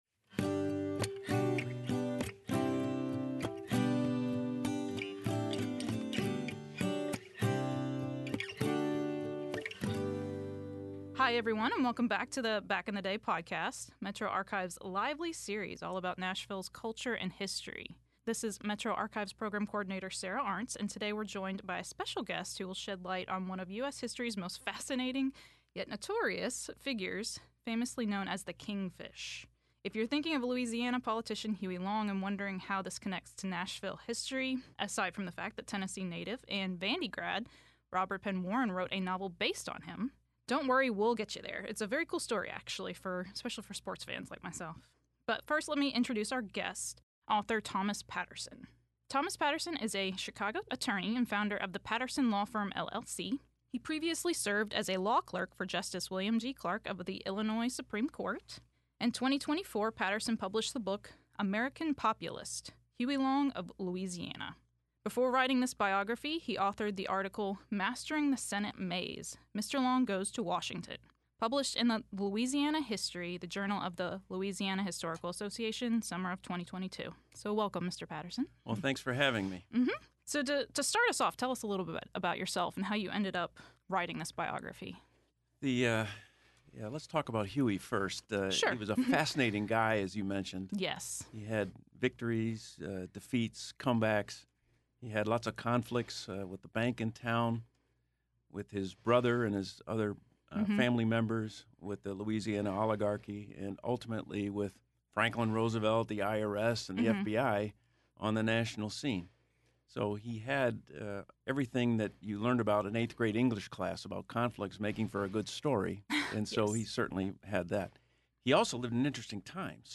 Back in the Day Podcast is a series of interviews with historians, related to Tennessee and Middle Tennessee history.